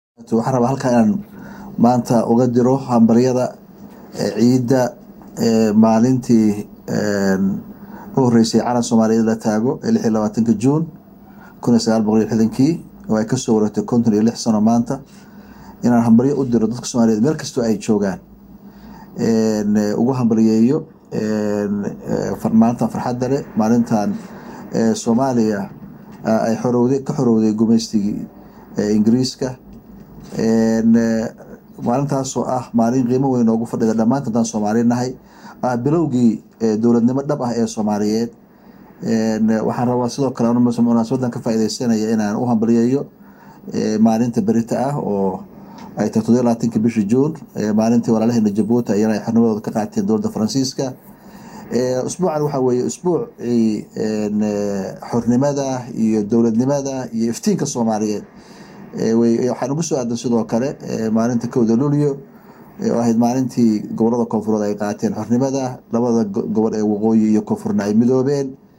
Madaxweynaha Puntland oo u hambalyeeyay Somalida maalinta 26 Juun: MAQAL